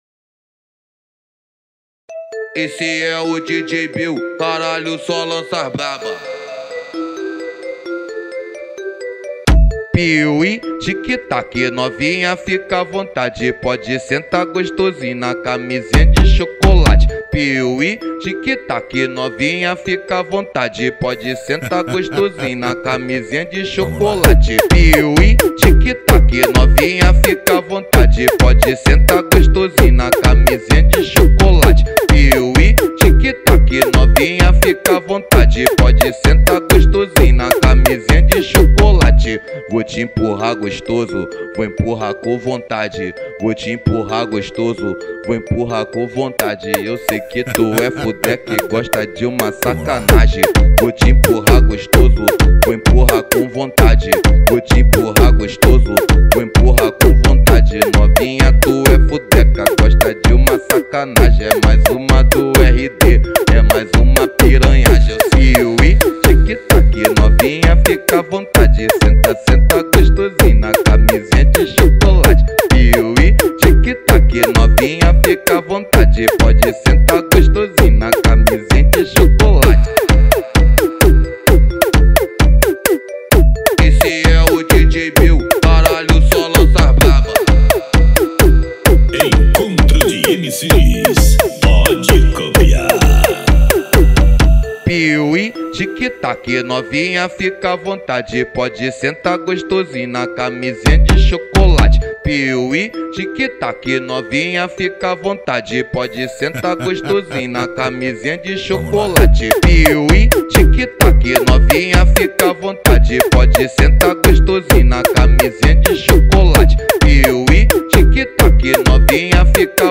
جذابیت آهنگ در انرژی خام و بیس کوبنده‌ی آن نهفته است.
فانک